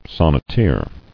[son·net·eer]